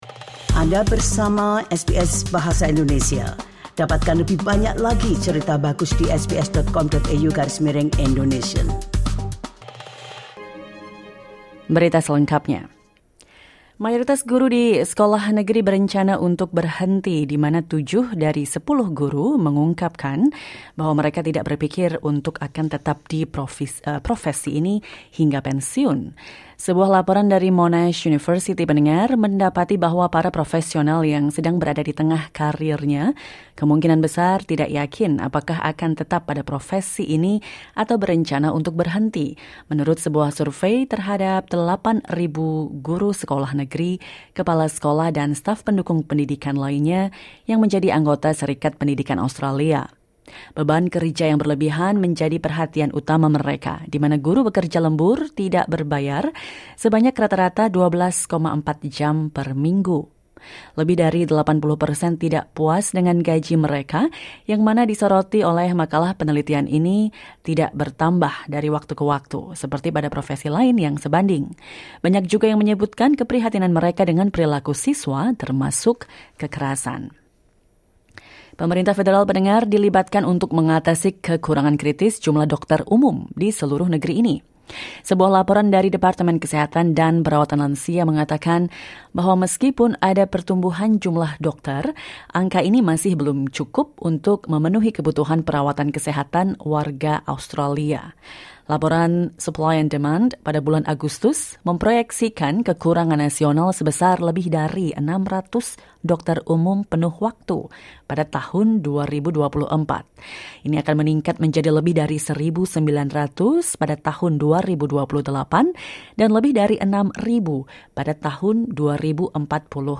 SBS Audio news in Indonesian - 9 August 2024